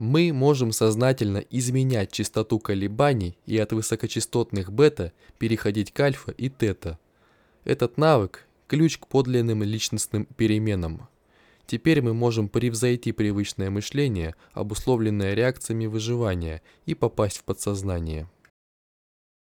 Зажатый голос на FIFINE K683A
Имеется микрофон FIFINE K683A.
Голос звучит как-то зажато и неестественно, не раскрывается полностью, как будто...